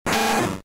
Cri de Rattatac K.O. dans Pokémon Diamant et Perle.